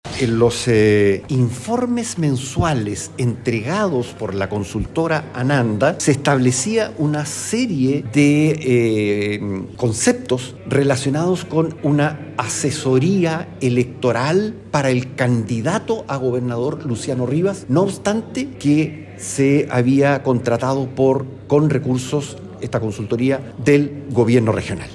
El actual Gobernador de La Araucanía señaló a La Radio que estas prestaciones se contrataron con recursos del Gobierno Regional.
cu-rene-saffirio.mp3